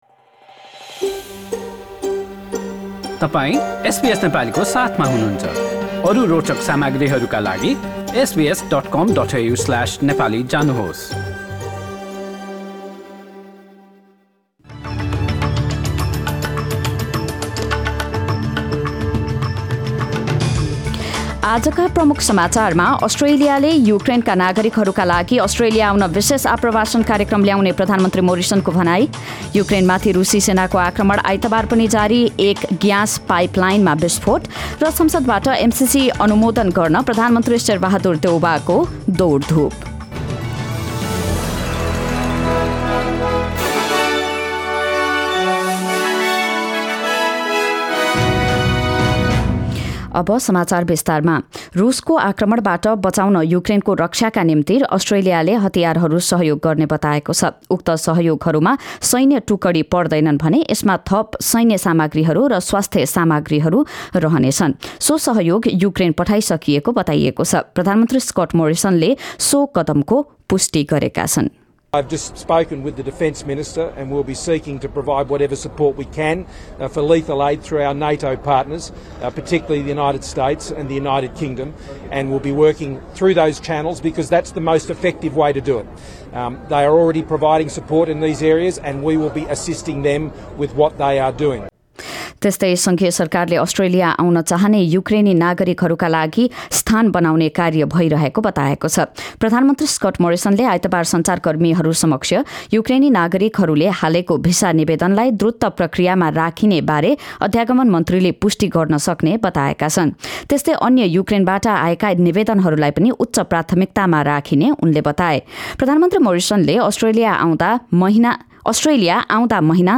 एसबीएस नेपाली अस्ट्रेलिया समाचार: आइतबार २७ फेब्रुअरी २०२२